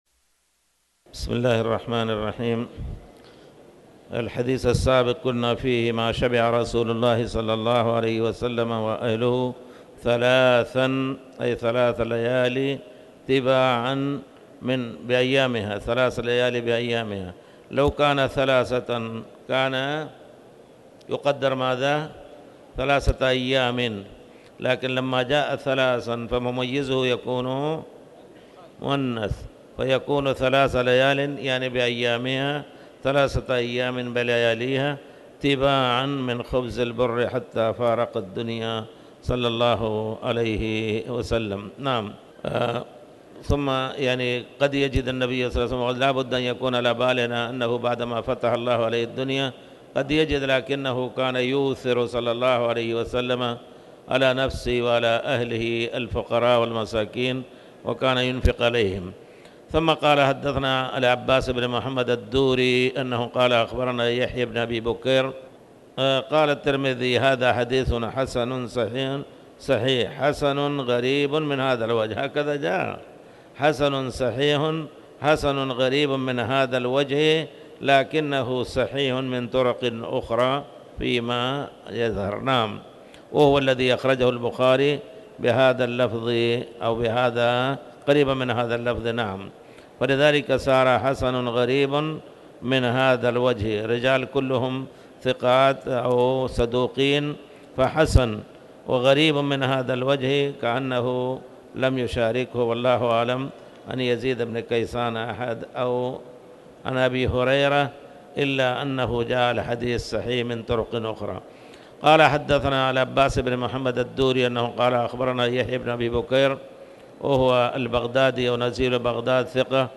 تاريخ النشر ١٥ جمادى الأولى ١٤٣٩ هـ المكان: المسجد الحرام الشيخ